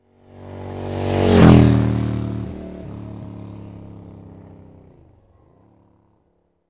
Index of /90_sSampleCDs/AKAI S6000 CD-ROM - Volume 6/Transportation/MOTORCYCLE
1200-PASS.WAV